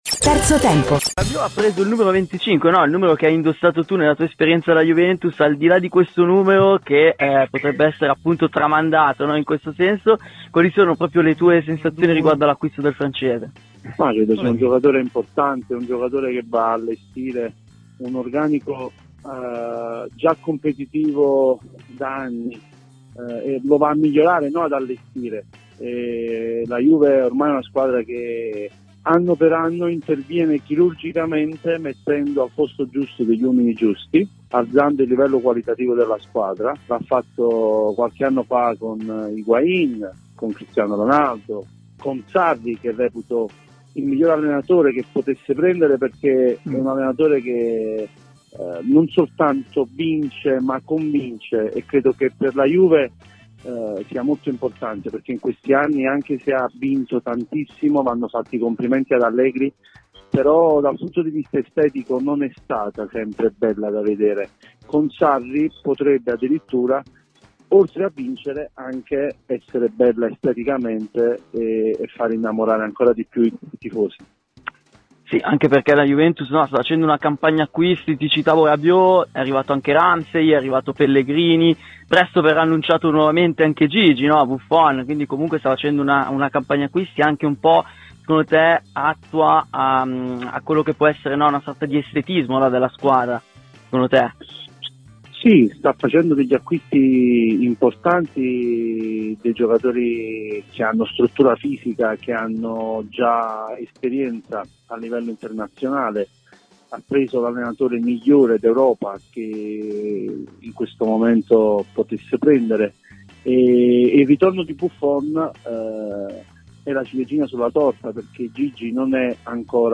Gugliemo Stendardo ai microfoni di "Terzo tempo" su Radio Bianconera,